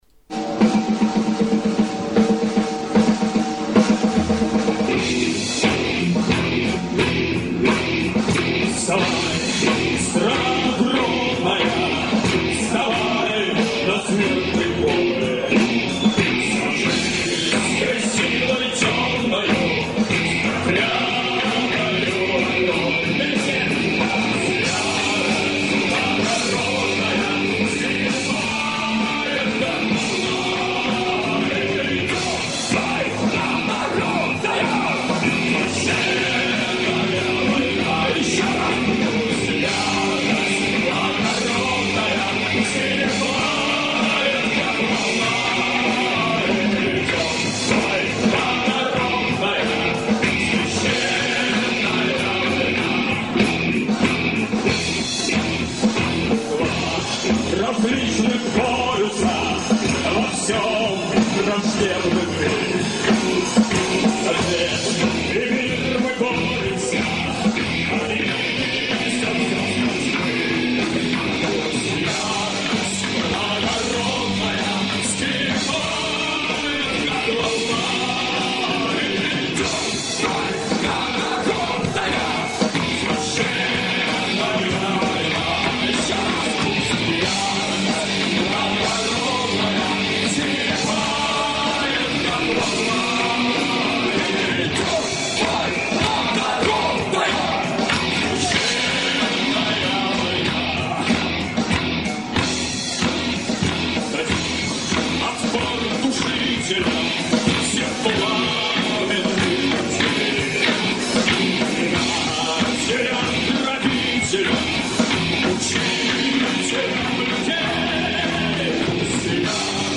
Live (современная обработка)